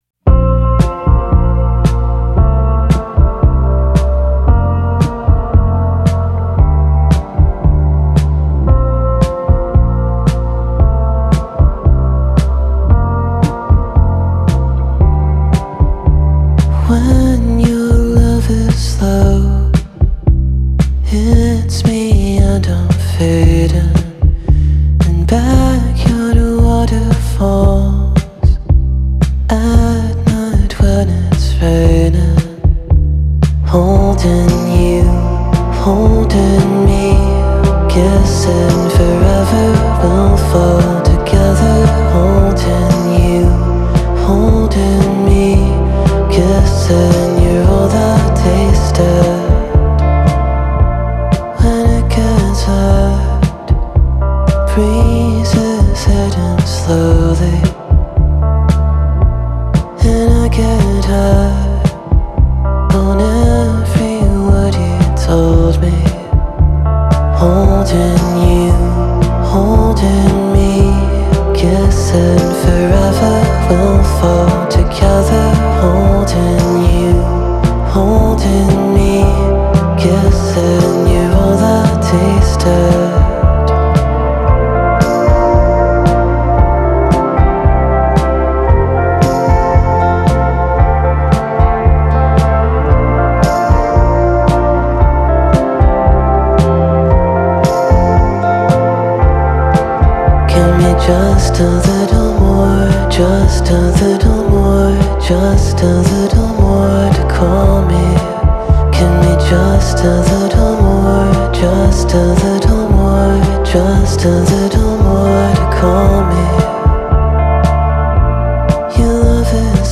Отличная баллада